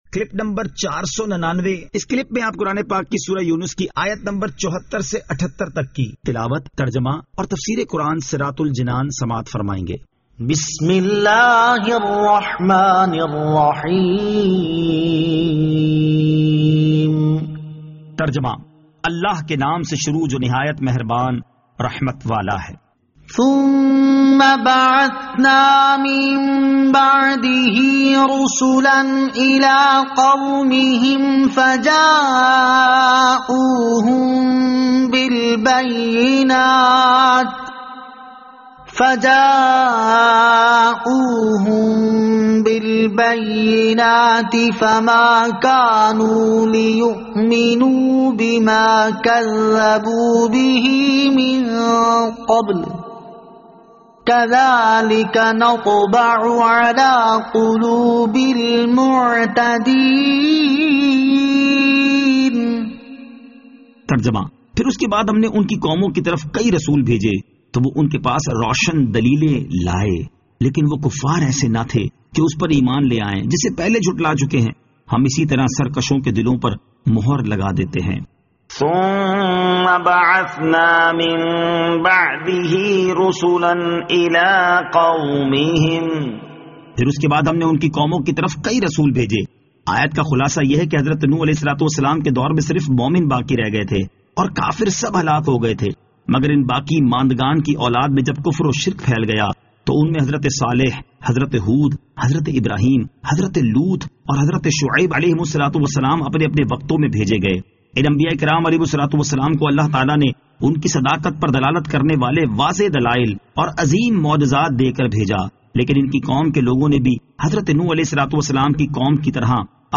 Surah Yunus Ayat 74 To 78 Tilawat , Tarjama , Tafseer